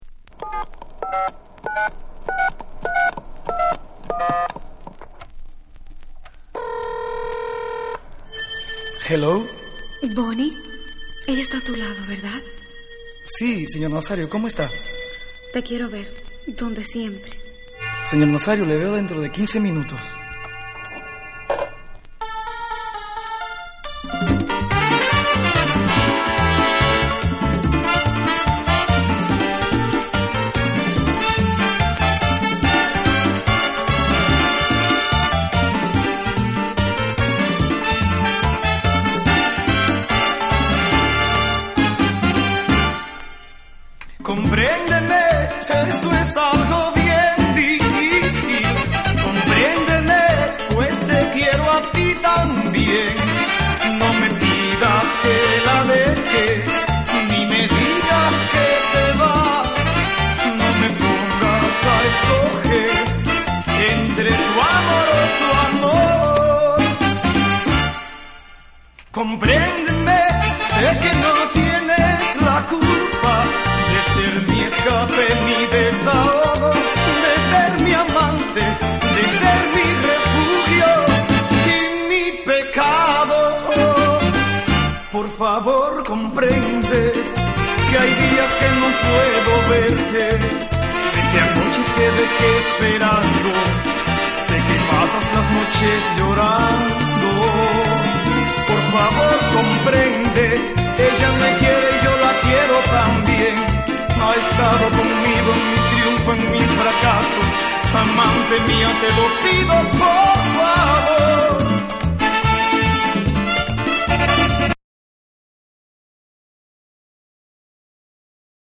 80年代特有の打ち込みビートやシンセ・アレンジが効いたメレンゲ・ナンバーを両面に収録。